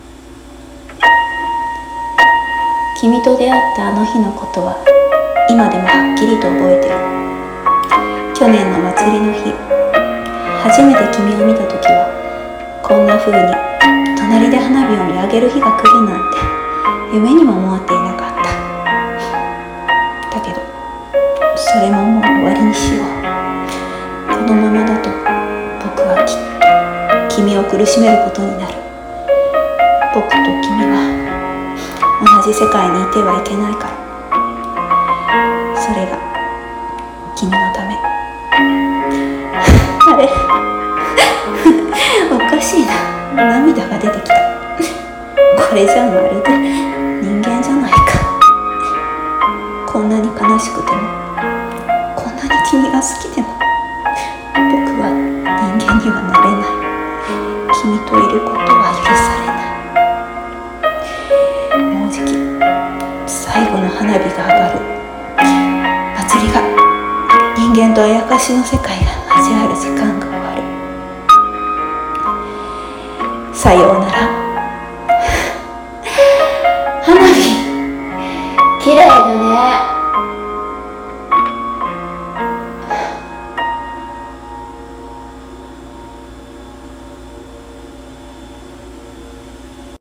【1人声劇】最後の花火